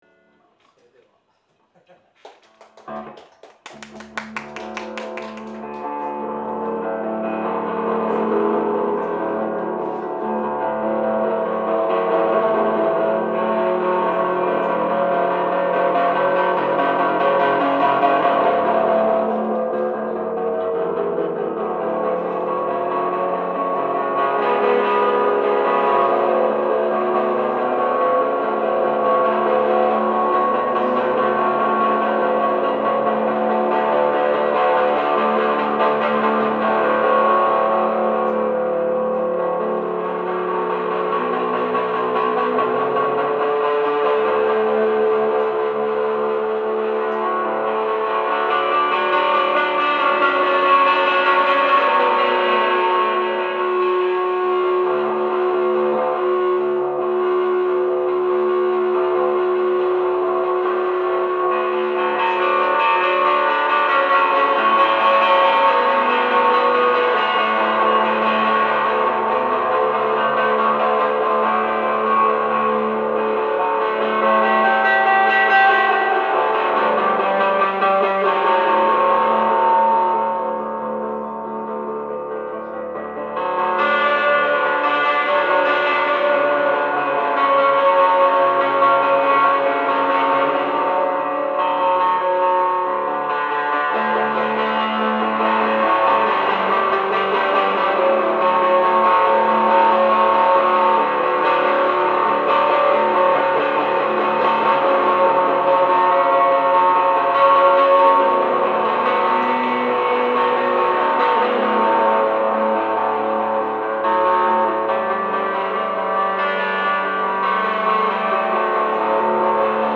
solo improvisation Live at Umejima YUKOTOPIA